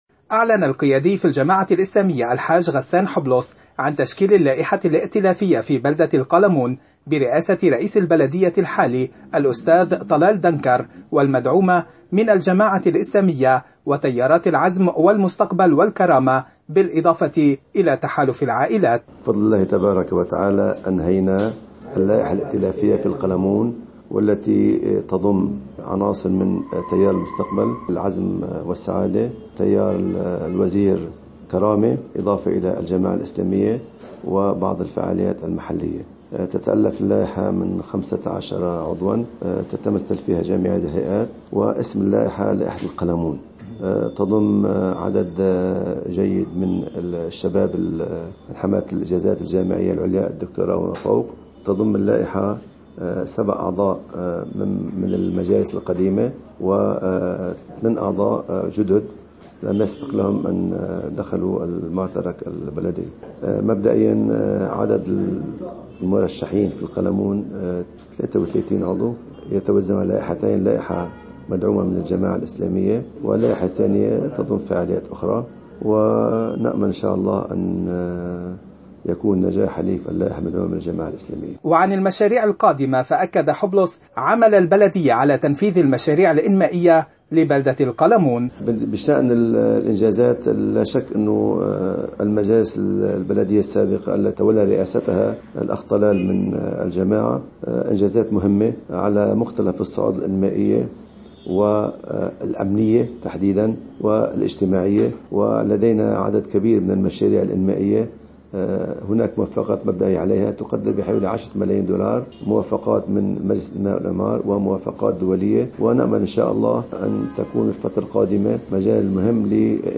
مقابلة إذاعة الفجر